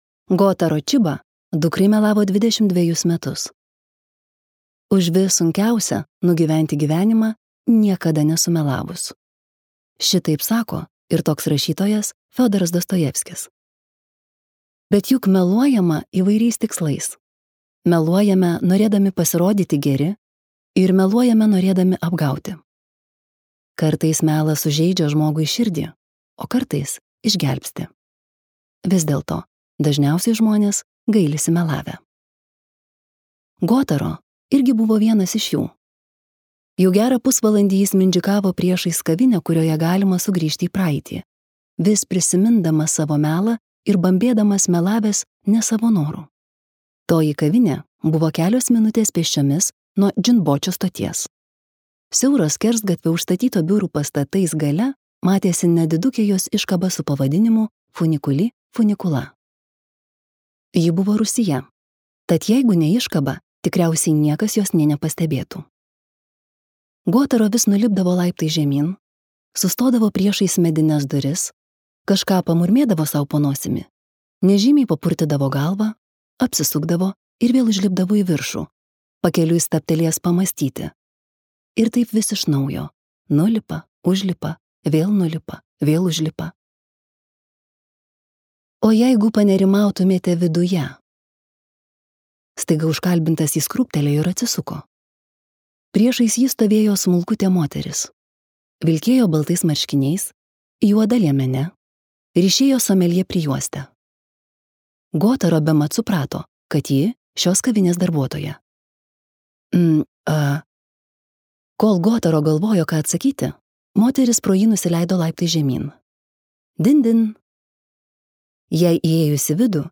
Kol dar neišaiškėjo tiesa | Audioknygos | baltos lankos